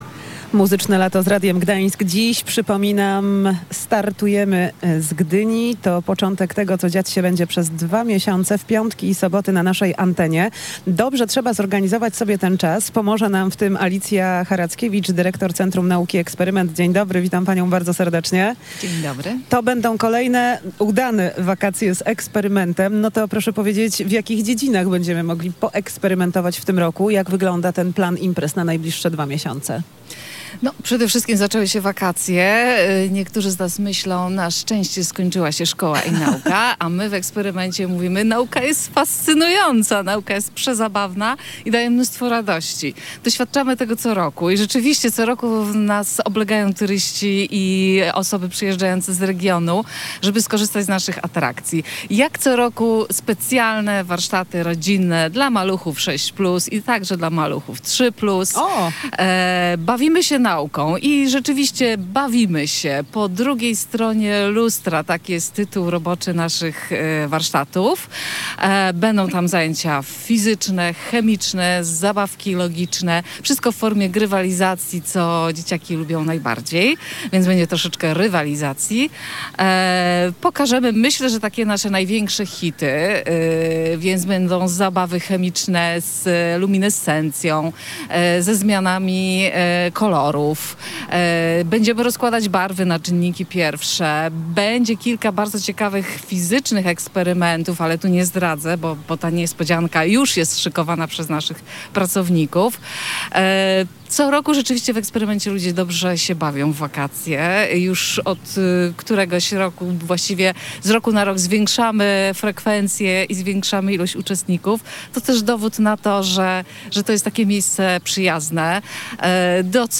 Radio Gdańsk w „mieście z morza”. Nasze mobilne studio stanęło na Skwerze Kościuszki w Gdyni
W piątek, 24 czerwca, nasze mobilne studio zawitało do Gdyni, gdzie spotkaliśmy się z przedstawicielami miasta, a także skosztowaliśmy pierwszego w tym roku produktu kandydującego do miana „Pomorskiego Przysmaku”.